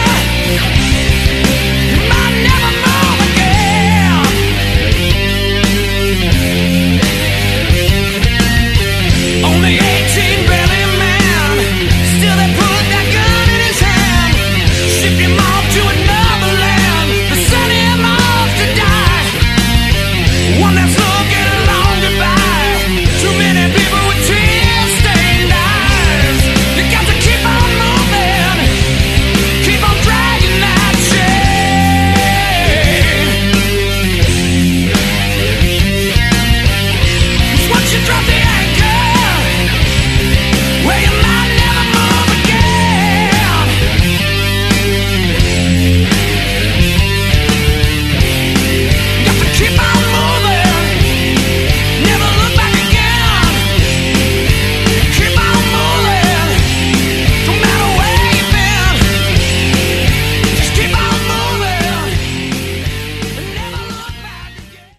Category: Hard Rock
bass
vocals
guitar
drums